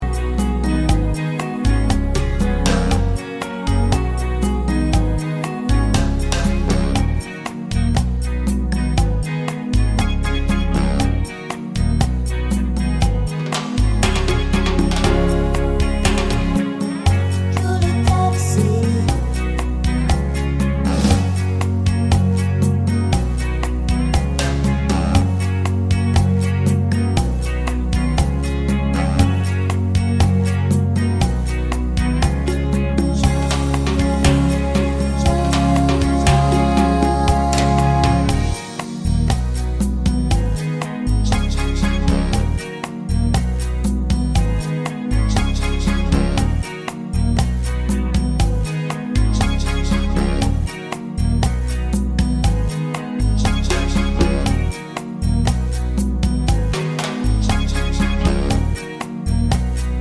Minus Sax Ex Baritone Pop (1980s) 3:35 Buy £1.50